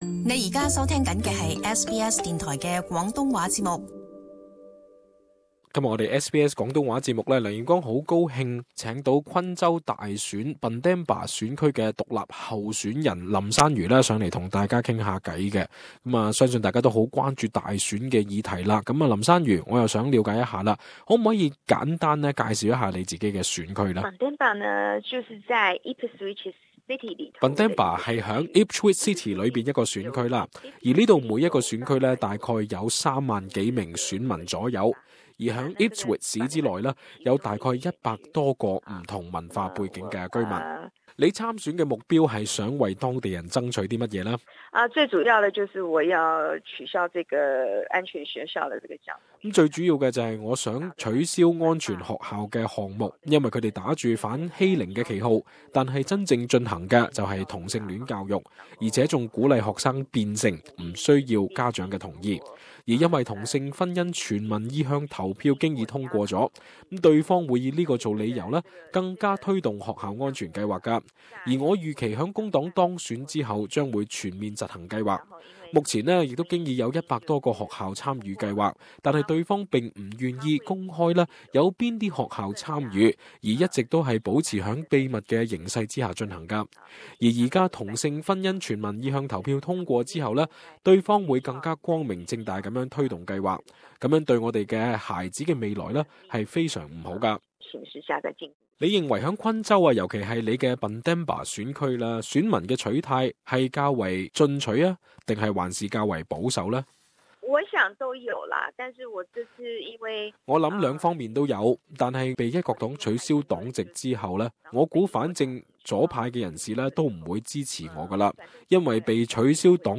【昆州議會選舉專訪】兩位華人參選人細說目標與政綱